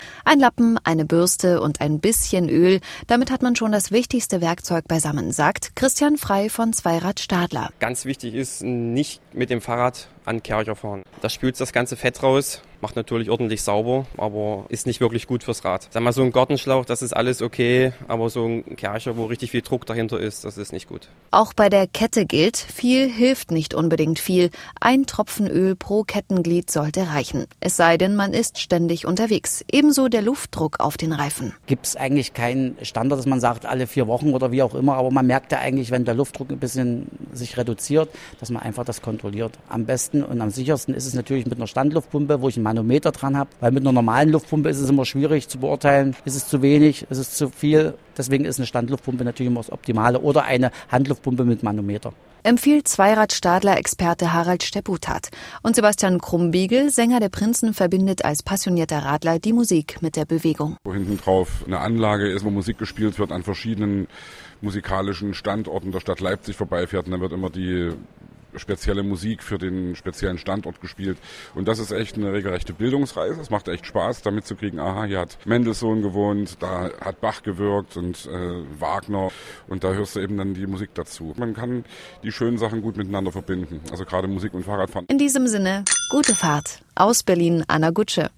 O-Töne / Radiobeiträge, , ,